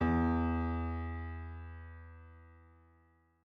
multiplayerpiano / sounds / SoftPiano / e1.mp3
e1.mp3